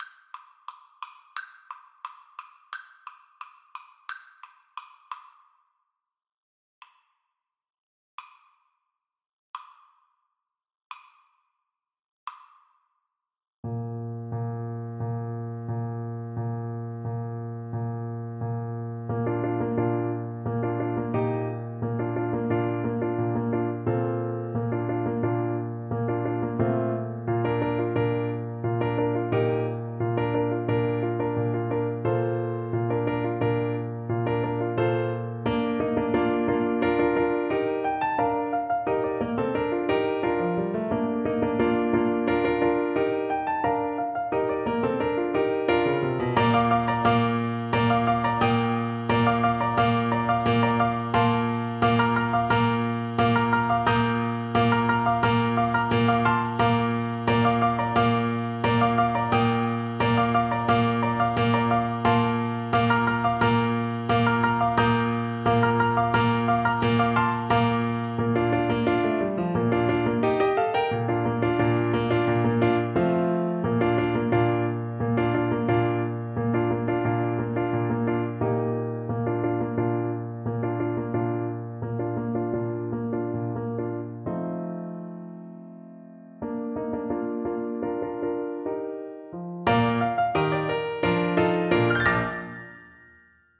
Clarinet
Traditional Music of unknown author.
Allegro Energico = c.88 (View more music marked Allegro)
2/2 (View more 2/2 Music)
Bb major (Sounding Pitch) C major (Clarinet in Bb) (View more Bb major Music for Clarinet )